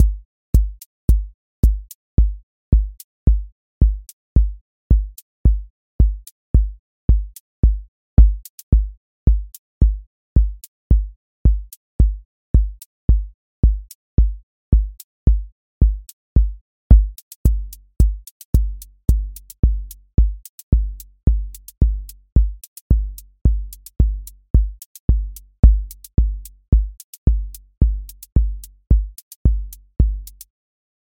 QA Listening Test house Template: four_on_floor
• macro_house_four_on_floor
• voice_kick_808
• voice_hat_rimshot
• voice_sub_pulse